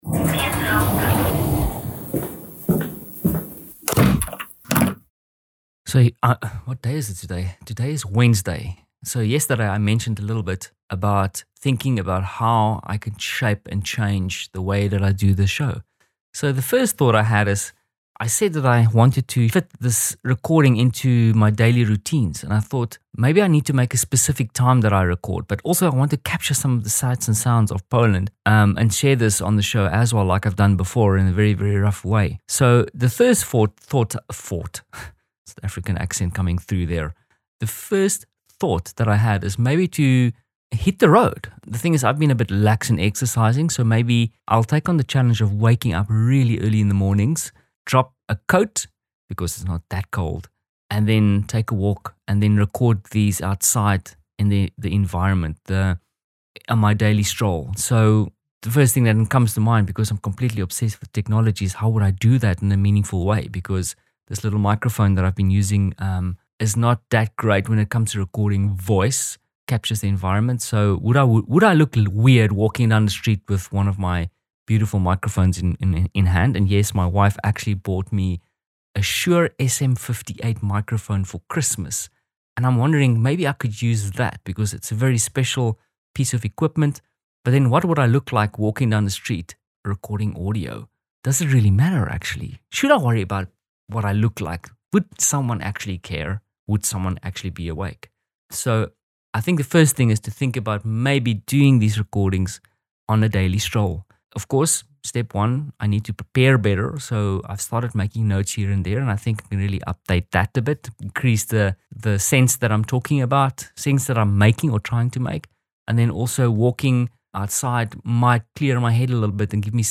#8 Prototyping the first field recording
#8 Giving some thought about how I would like to play with this format and ended up in the city taking a stroll down Marszałkowska Street past the old Palace of Culture.